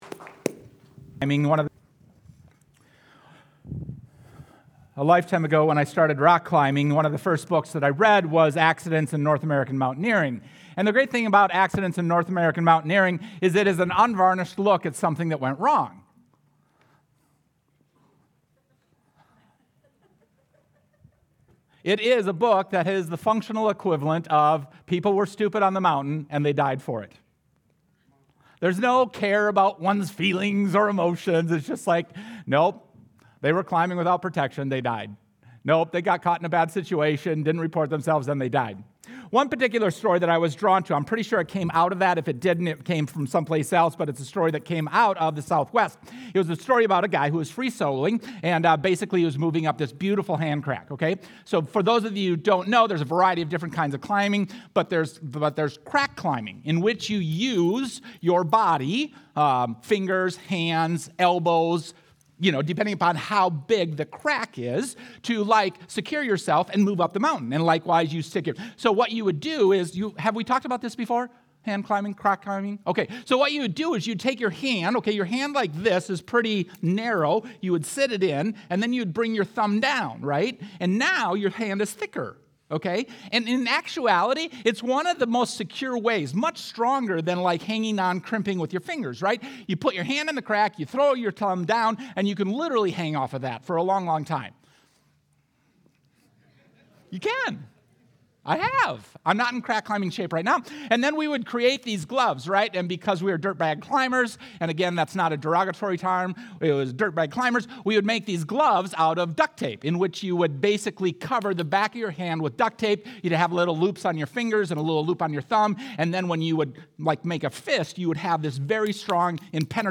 Sunday Sermon: 6-22-25